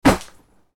Inventory Item Pick Up Sound Effect
Description: Inventory item pickup sound effect: the sound of picking up an item to be added to the player’s inventory. Game sounds.
Inventory-item-pick-up-sound-effect.mp3